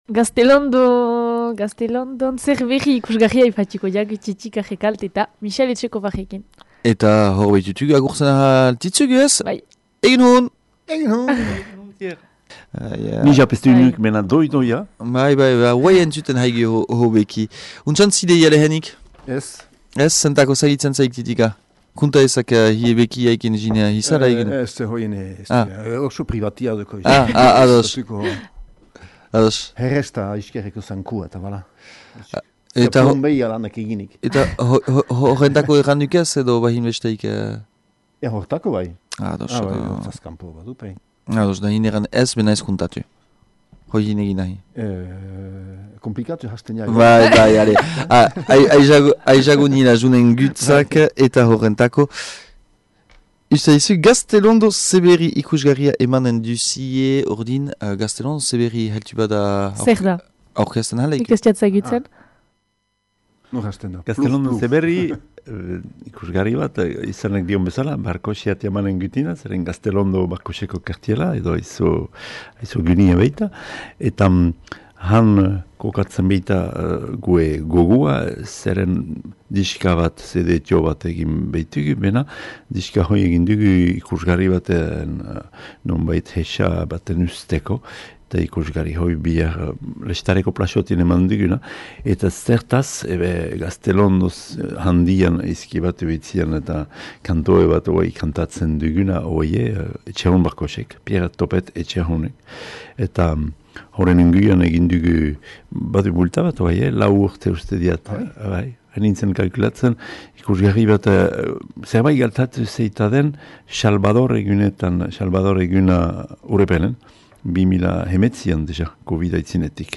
üken dütügü xüxenean goiz hontan :